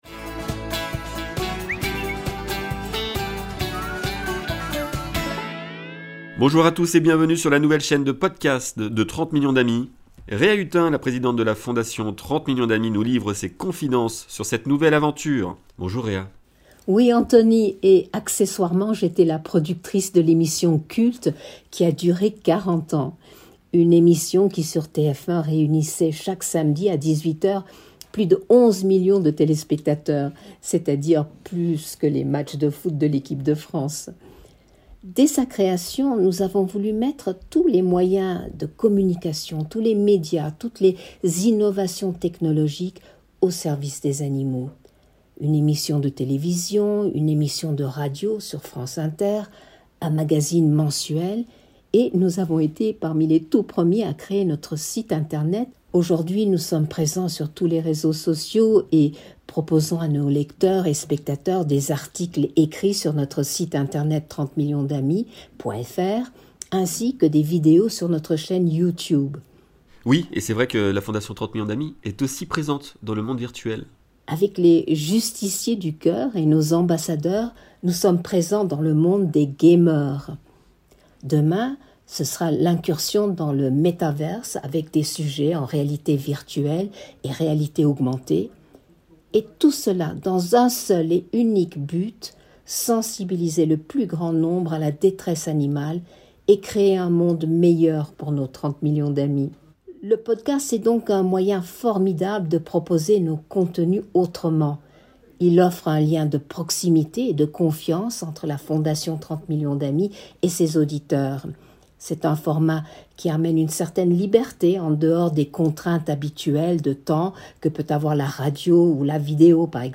interview__0_Reha.mp3